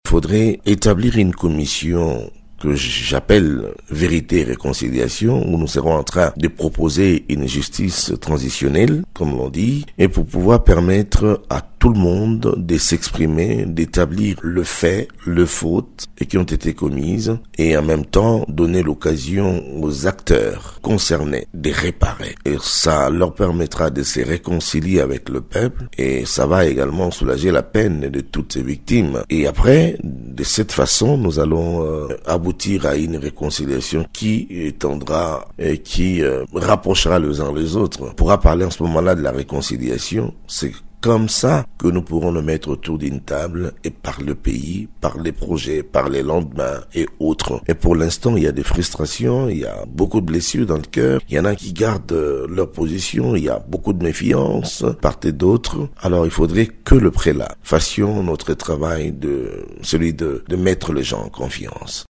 Le Conseil interreligieux congolais (CIC) recommande la création d’une commission Vérité et réconciliation (CVR) avant la tenue de tout dialogue en RDC. Lors d’une interview accordée lundi 20 octobre à Radio Okapi, le président du CIC, l’archevêque Dodo Kamba a souligné que cette commission devrait permettre aux acteurs en conflit de se rapprocher les uns des autres.